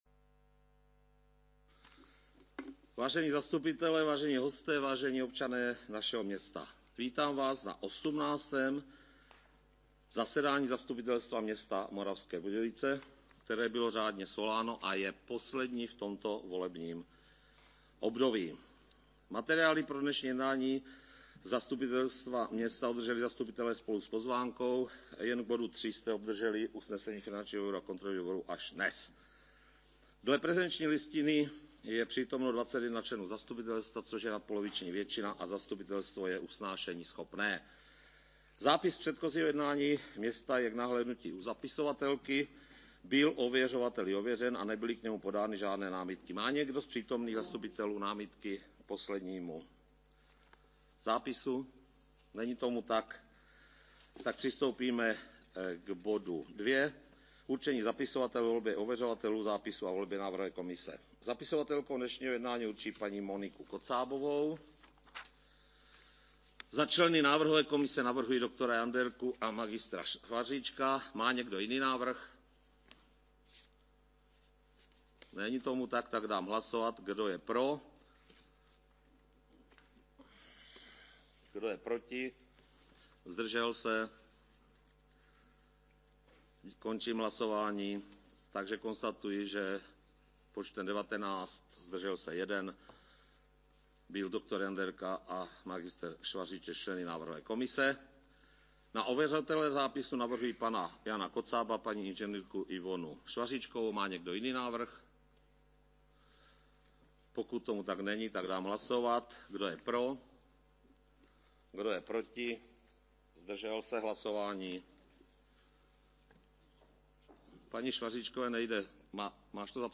Město Moravské Budějovice: Pseudoanonymizovaný záznam z 18. zasedání Zastupitelstva města Moravské Budějovice 5. 9. 2022 Záznam z 18. zasedání Zastupitelstva města Moravské Budějovice 5. 9. 2022 fe99c212d18e65ea22da137e4699b64e audio